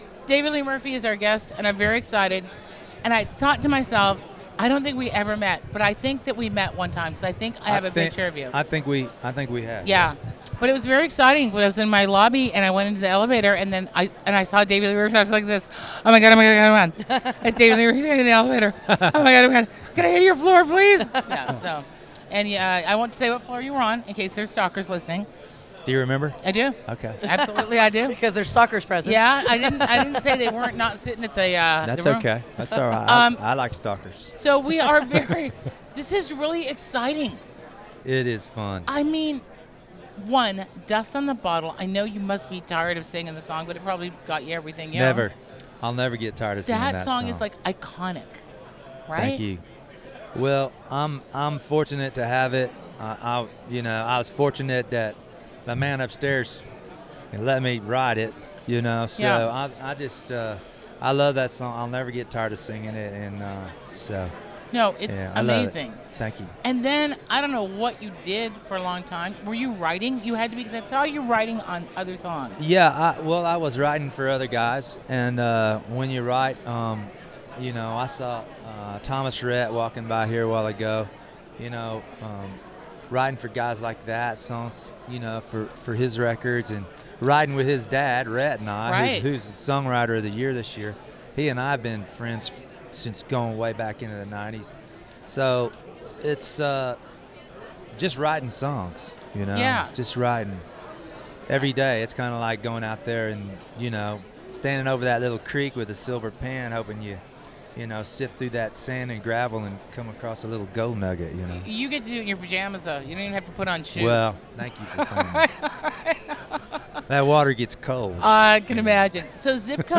David Lee Murphy Interview At 2018 ACMs!